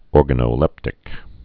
(ôrgə-nō-lĕptĭk, ôr-gănə-)